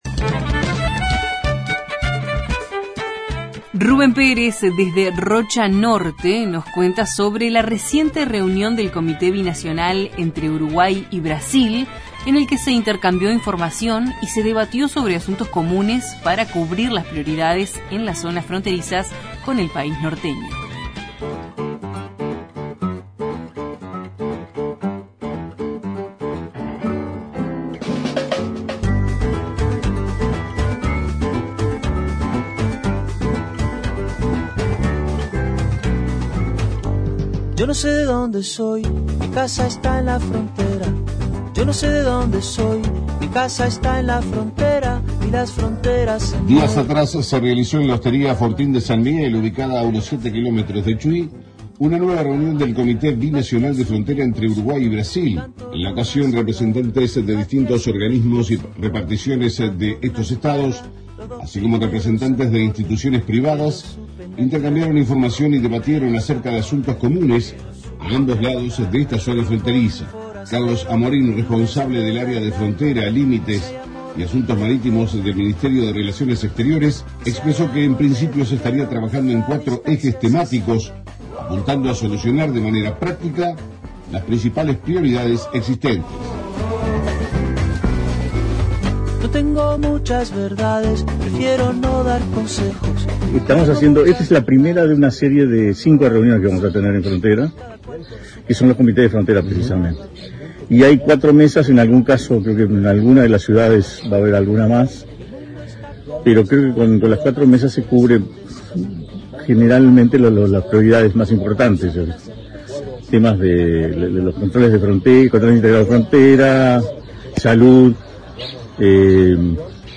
Los informes de nuestros corresponsales de Rocha Norte, Colonia Oeste y Treinta y Tres.